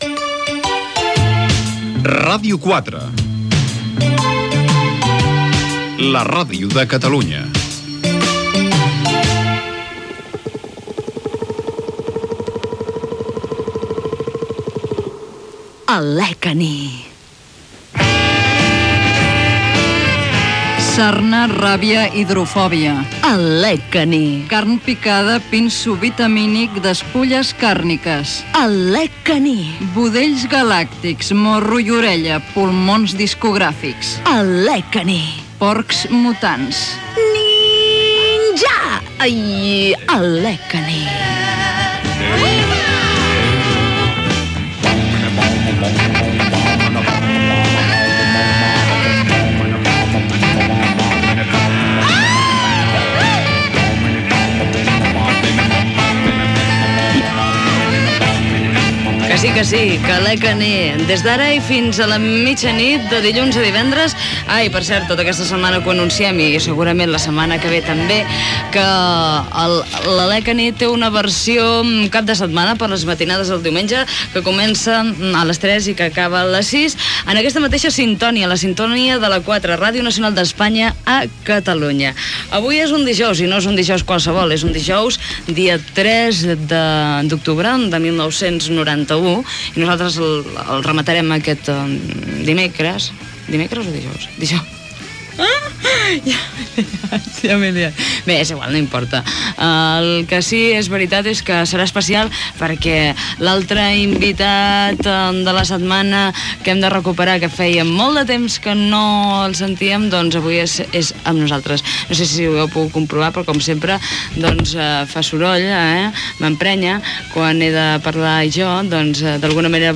Indicatiu de la ràdio, careta del programa, presntació, comentaris inicials, tema musical, comentari del tema escoltat, entrevista al cantant Adrià Puntí Gènere radiofònic Entreteniment